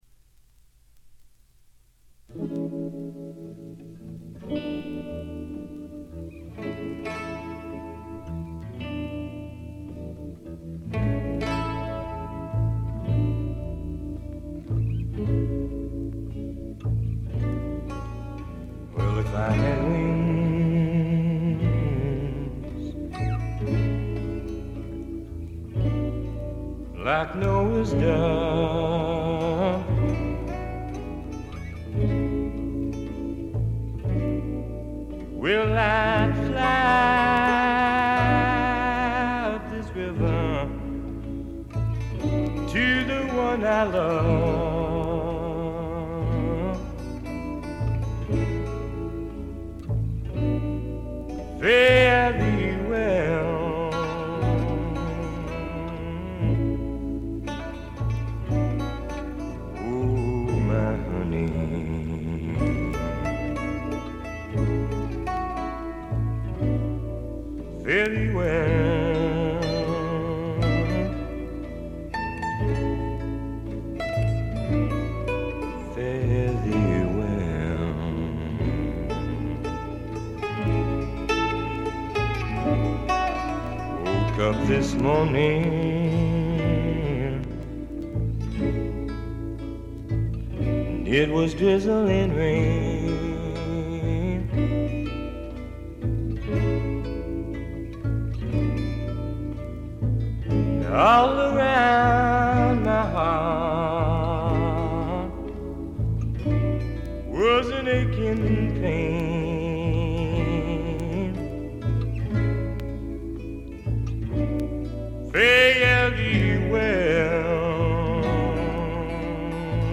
ごくわずかなノイズ感のみ。
言わずとしれた60年代を代表するフォーク／アシッド・フォークの大名作ですね。
地を這うように流れ出すヴォーカルには底なし沼の深淵に引きずり込まれるような恐怖とぞくぞくする快感を覚えます。
モノラル盤。
試聴曲は現品からの取り込み音源です。